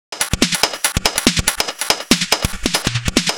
Index of /musicradar/uk-garage-samples/142bpm Lines n Loops/Beats